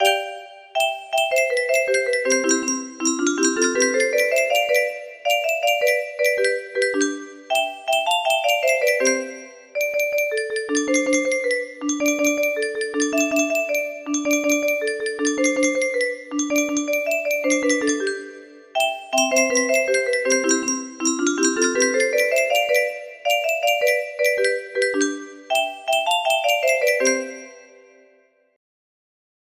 Wow! It seems like this melody can be played offline on a 15 note paper strip music box!